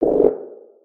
Sfx_creature_penguin_waddle_voice_02.ogg